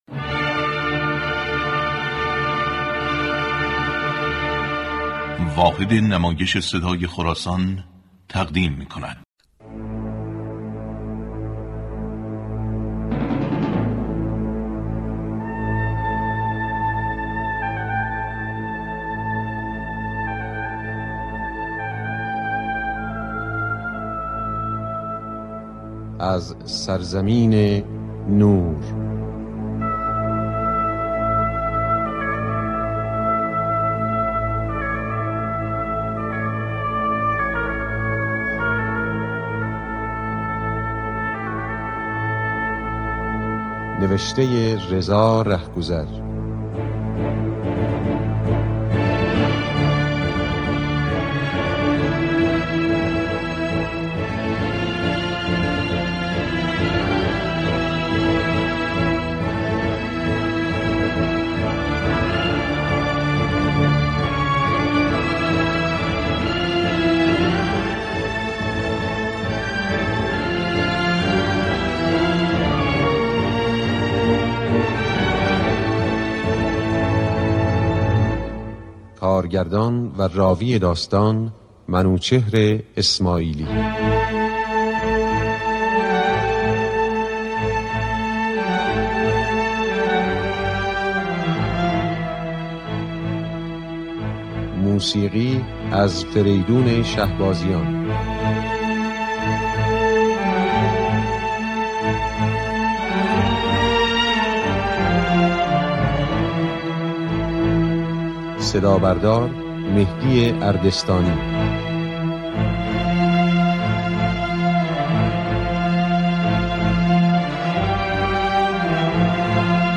"نمایش هفته" سه شنبه ها از رادیو تاجیکی پخش می شود.